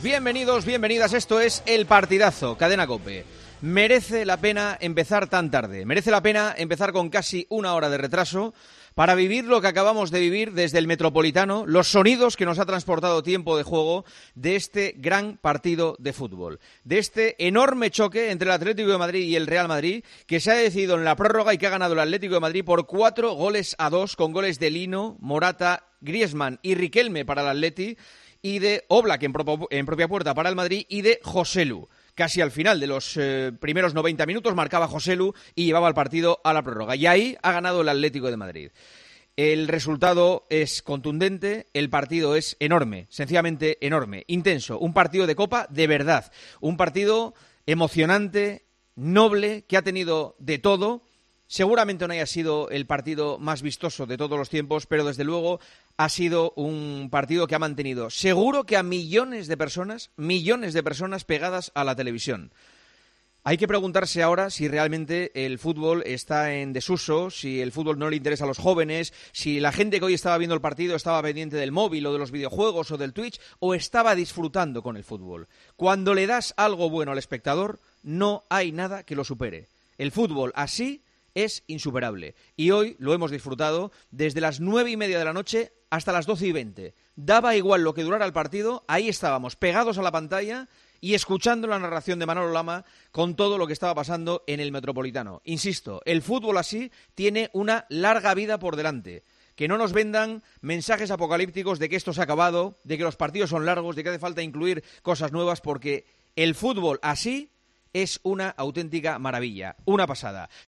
El director de El Partidazo de COPE mandó un mensaje defendiendo el fútbol actual tras el partidazo entre el Atlético de Madrid y el Real Madrid.